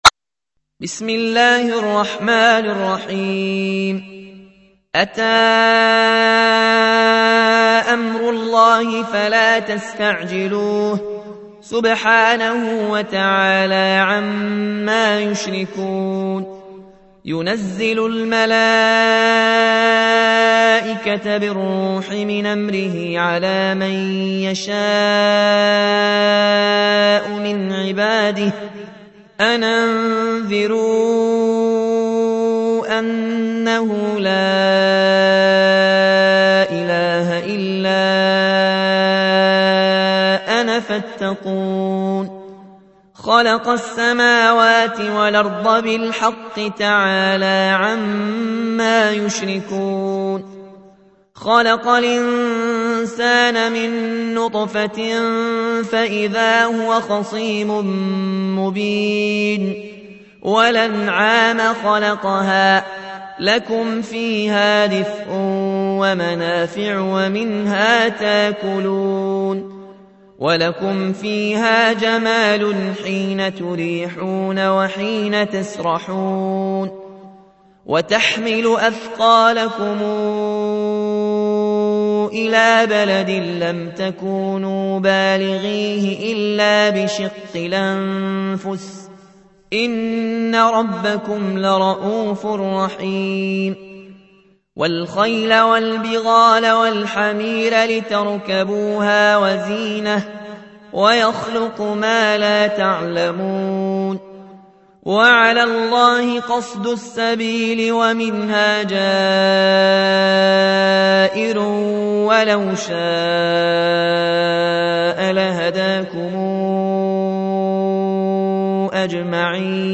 تحميل : 16. سورة النحل / القارئ ياسين الجزائري / القرآن الكريم / موقع يا حسين